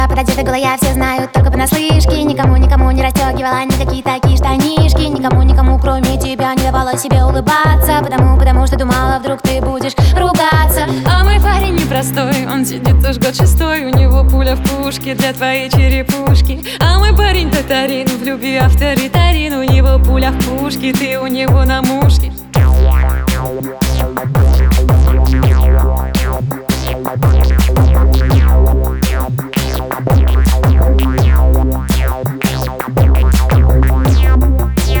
Жанр: Альтернатива / Русские